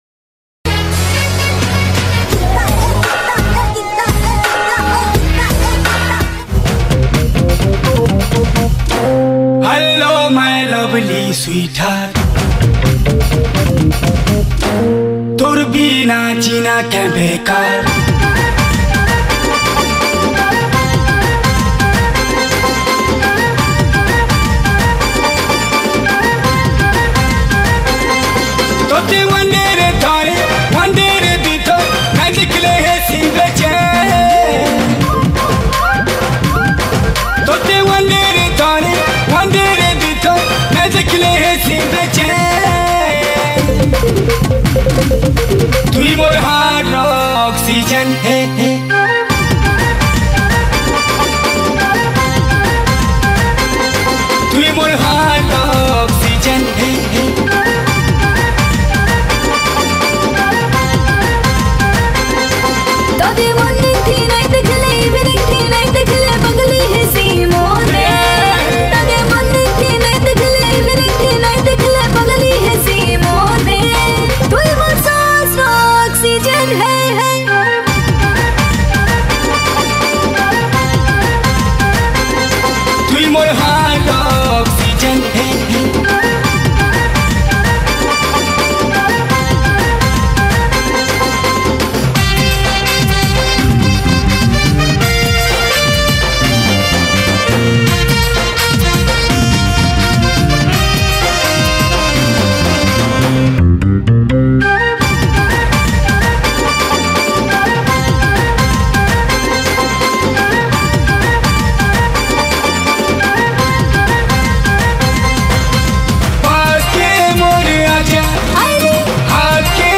Sambalpuri Song